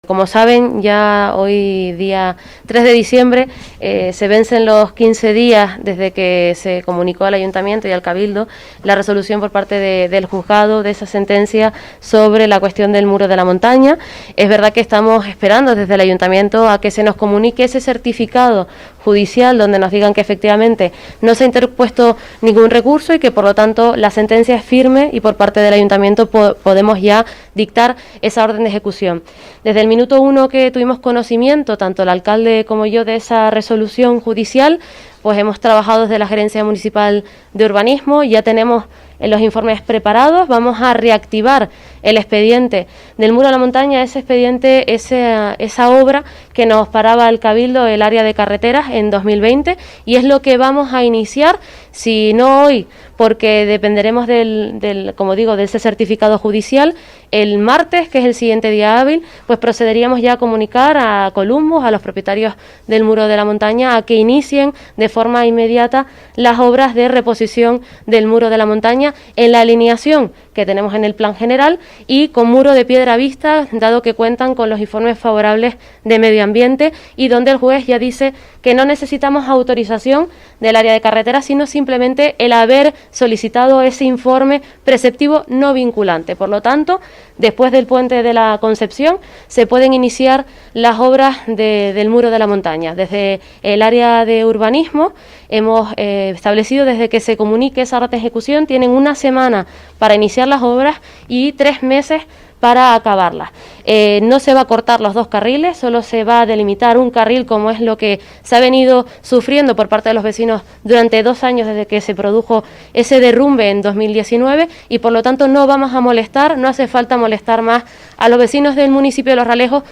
Los-Realejos.-Declaraciones-Laura-Lima-concejal-Urbanismo-sobre-muro-carretera-La-Montana-3-dic-2021.mp3